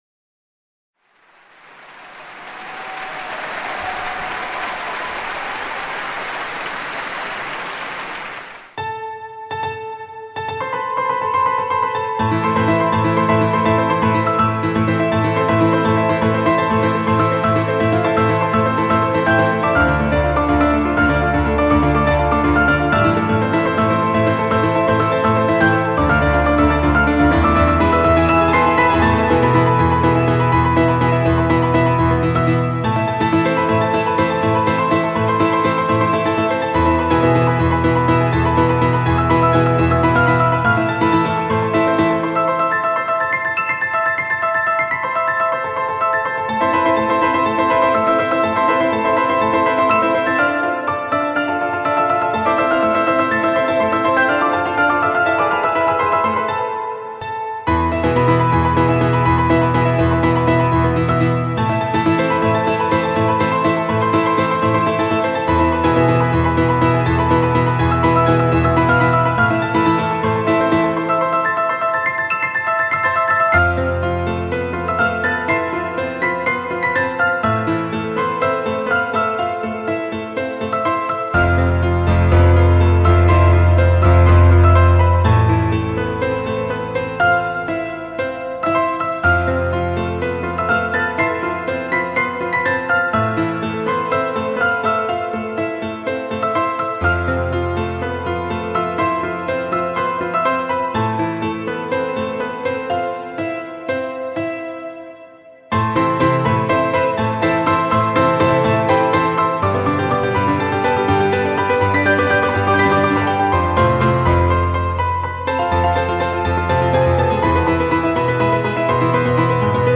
PIANO COVER (live parody)